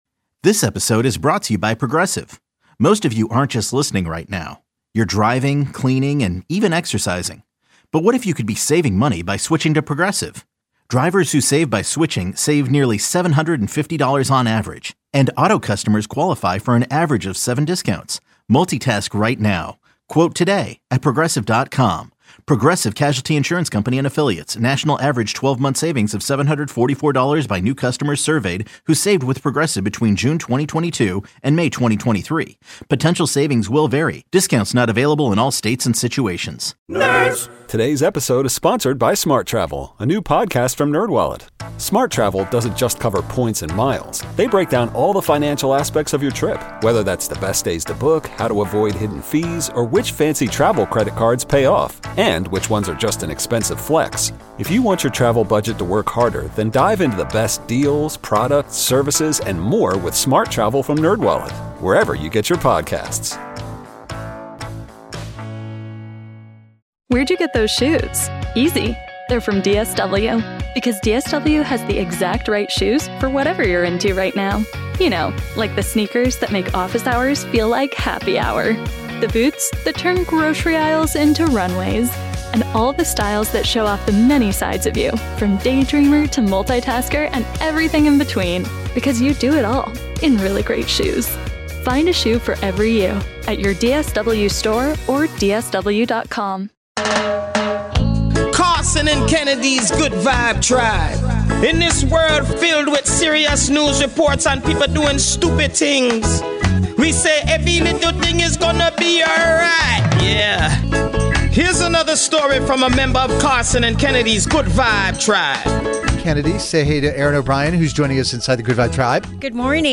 The show is fast paced and will have you laughing until it hurts one minute and then wiping tears away from your eyes the next.